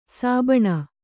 ♪ sābaṇa